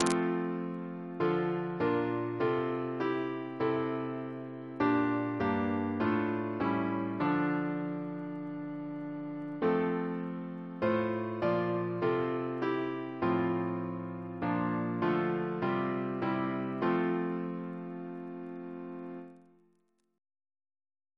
CCP: Chant sampler
Double chant in F Composer: Sir George Elvey (1816-1893), Organist of St. George's Windsor; Stephen's brother Reference psalters: ACB: 305